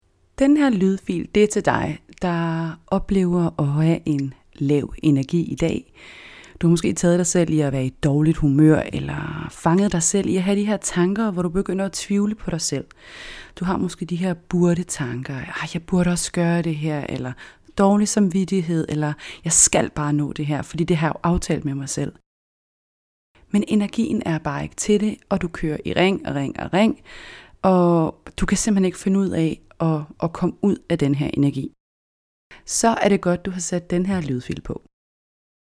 6 min. lydfil hvor jeg taler lidt mere direkte til dig og dine tanker. Tempoet er lidt højere end du måske er vant til fra andre meditationer, men det er helt med vilje.